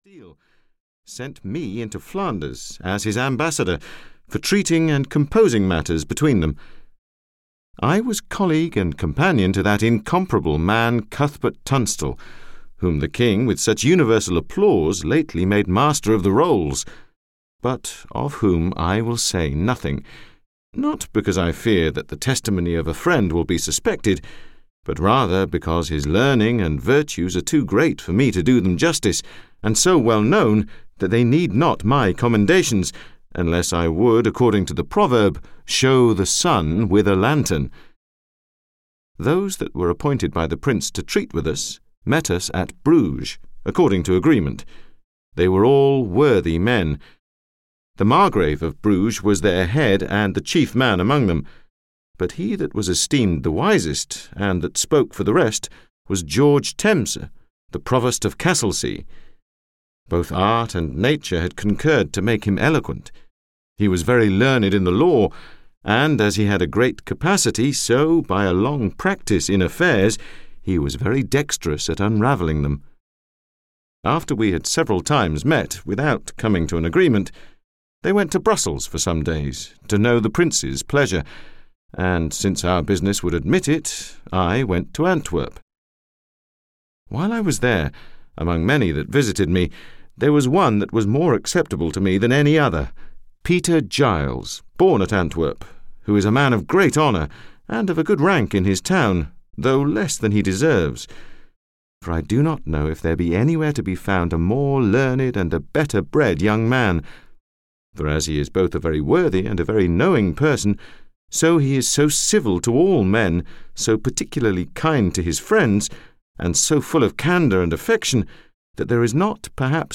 Utopia (EN) audiokniha
Ukázka z knihy